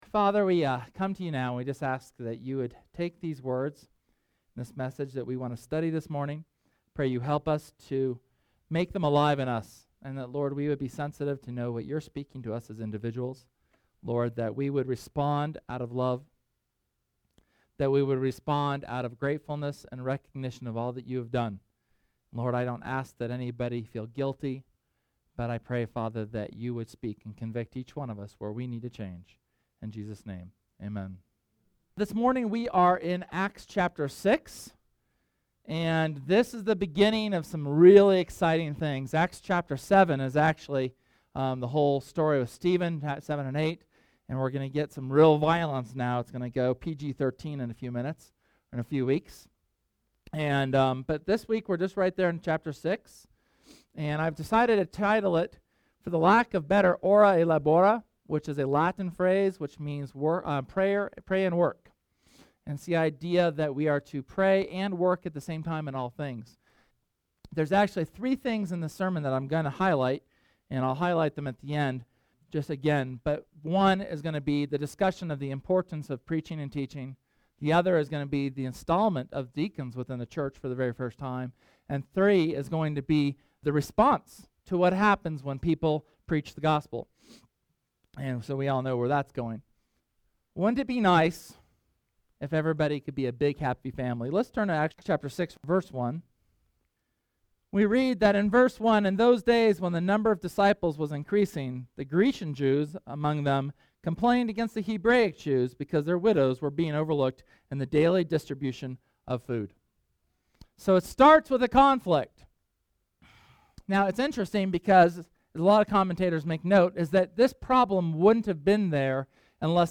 SERMON: Ora et Labora – Church of the Resurrection